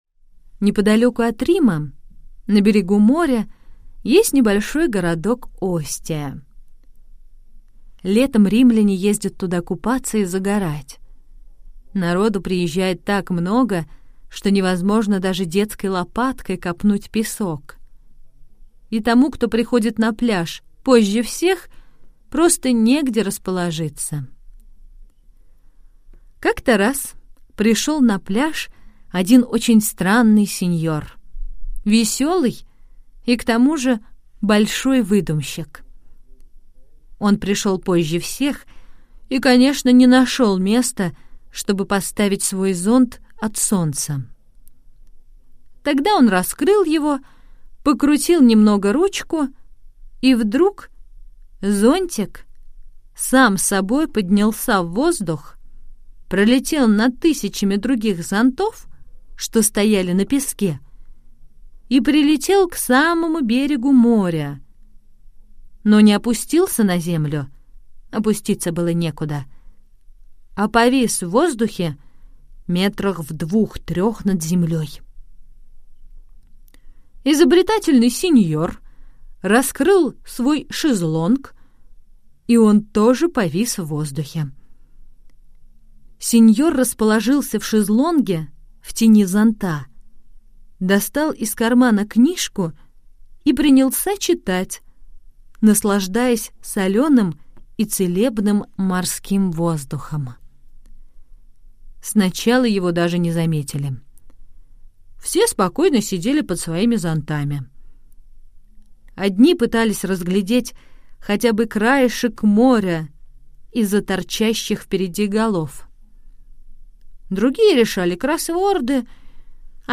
Аудиосказка «На пляже в Остии»